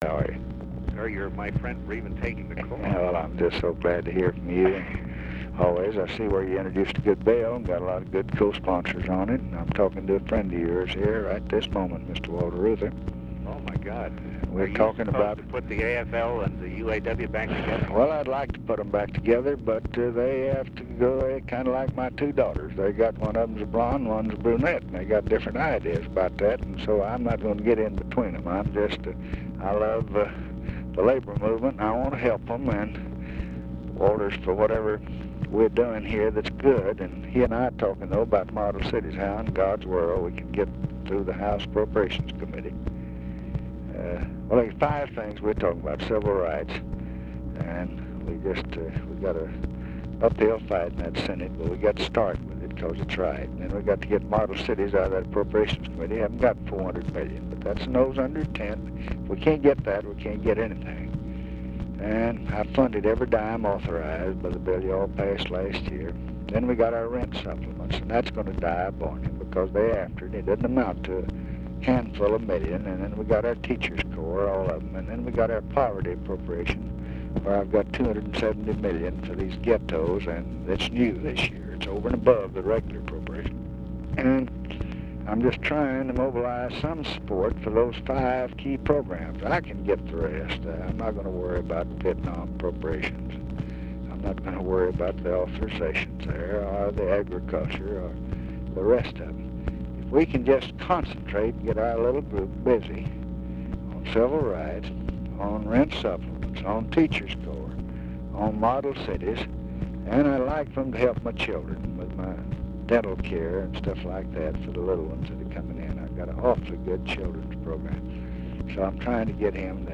Conversation with PHILIP HART, February 20, 1967
Secret White House Tapes